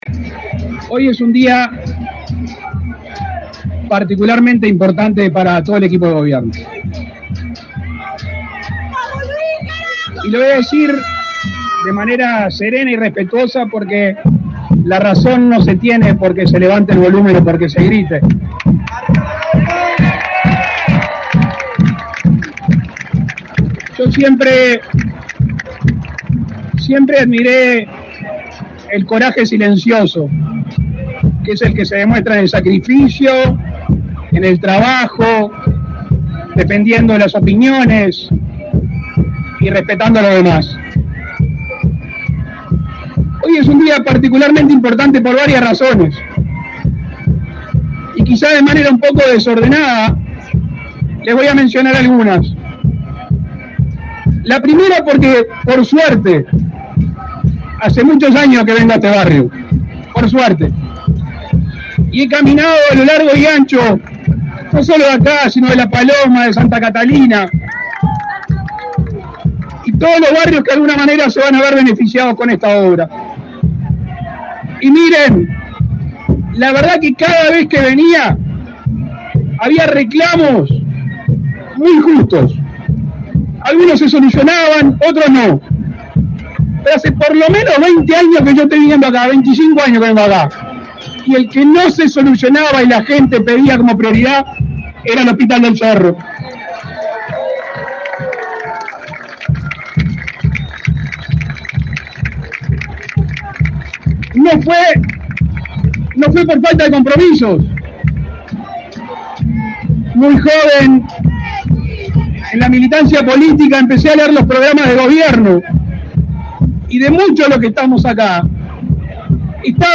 Palabras del presidente de la República, Luis Lacalle Pou
El presidente de la República, Luis Lacalle Pou, participó, este 6 de octubre, en la inauguración del Hospital del Cerro.